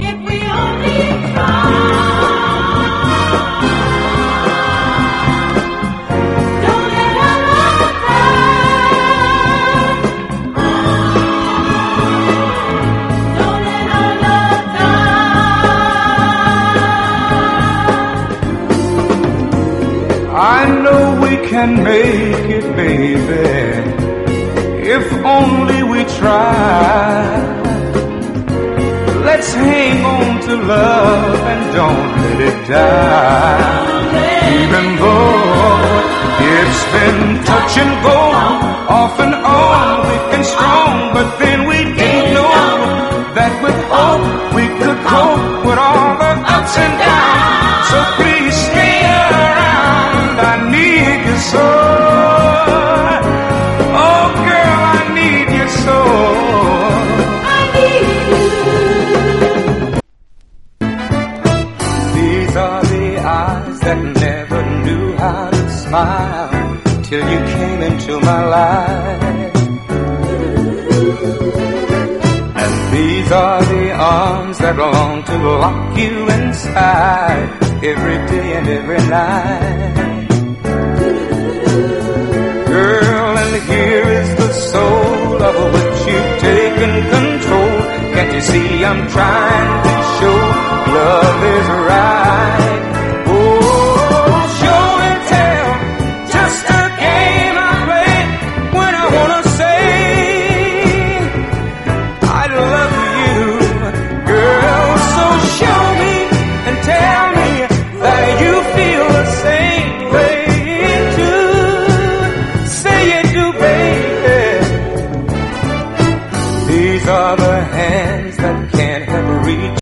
SOUL / SOUL / 70'S～ / MODERN SOUL / UK SOUL / R&B
アーバンでメロウなサウンド・プロダクションの素晴らしいトラックを収めています。